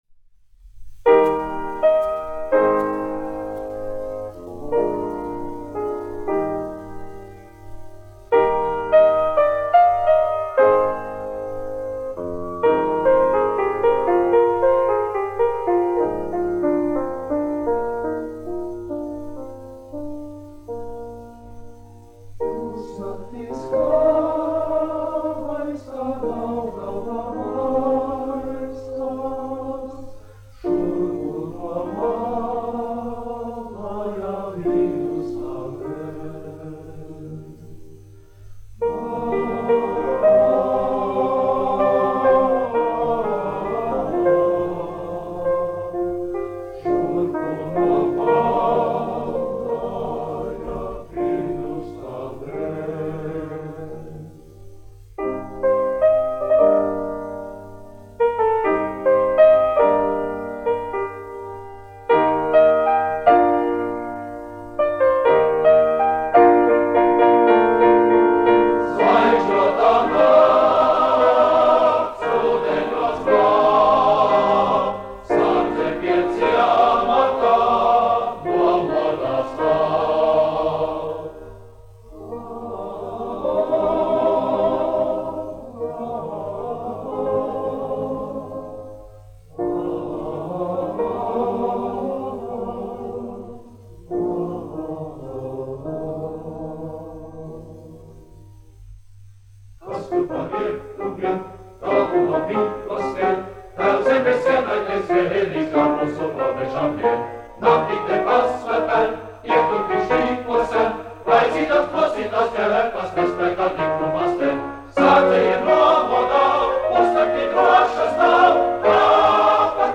1 skpl. : analogs, 78 apgr/min, mono ; 25 cm
Kori (vīru) ar klavierēm
Oratorijas--Fragmenti